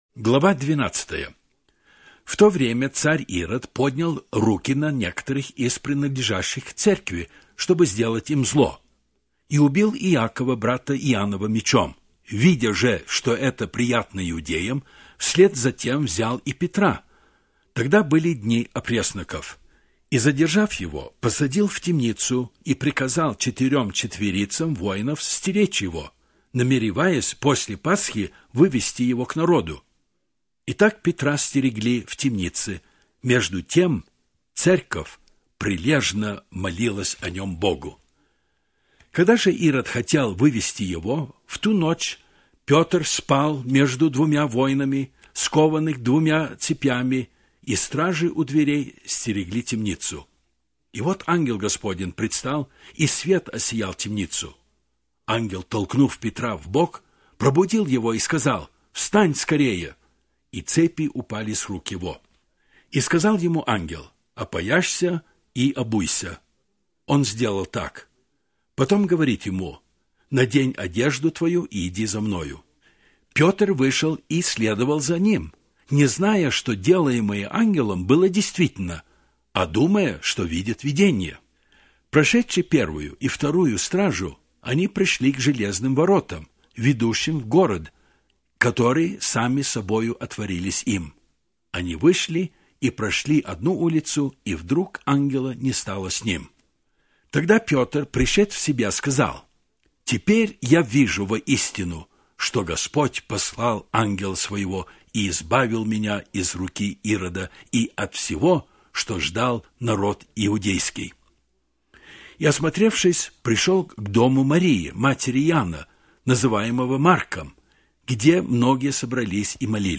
Аудио Библия